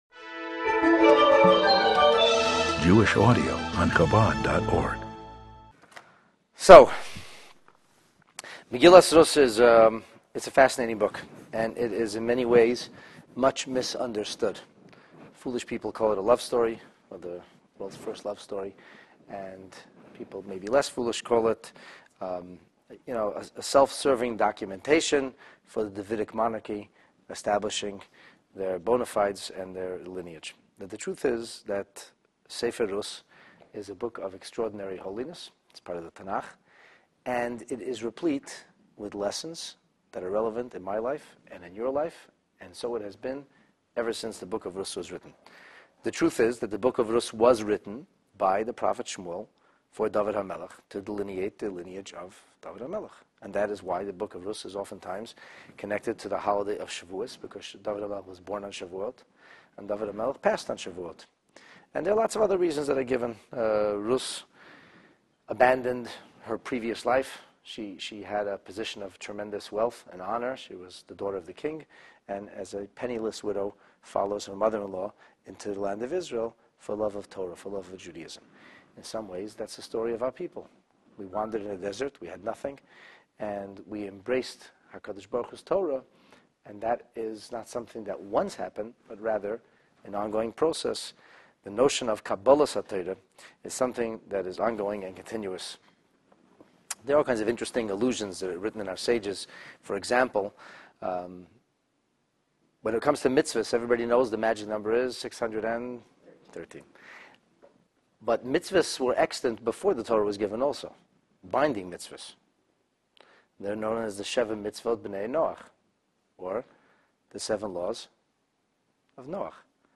Self-Preservation and Its Fatal Consequences: Megillat Rut in Depth, Chapter 1, Lesson 1 (PT 1)